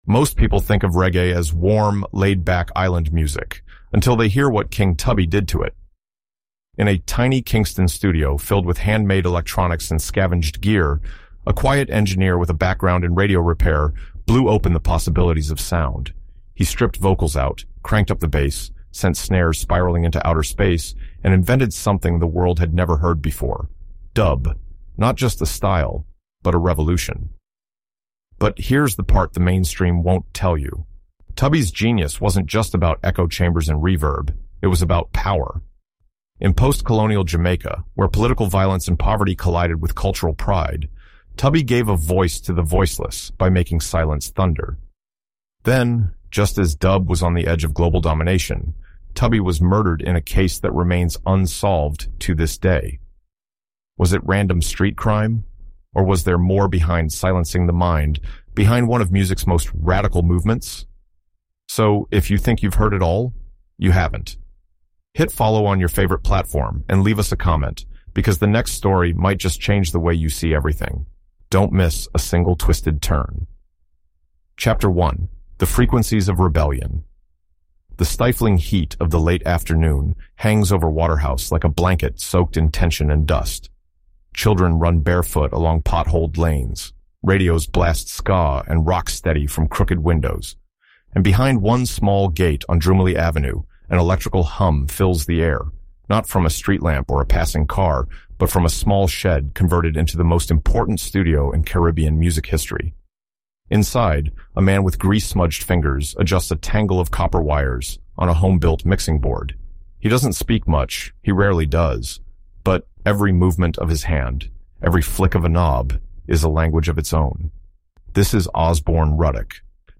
From his homemade studio, King Tubby weaponized bass and silence, shaping the reggae and dancehall soundscape that would later inspire legends from Lee “Scratch” Perry to today’s giants like Vybz Kartel. As we retrace Tubby’s journey—through the political turbulence of 1970s Jamaica, the cultural resilience of maroon communities, and the global spread of Caribbean music—you’ll hear from history experts, musicians, and travel experts who followed the sonic breadcrumbs he left behind.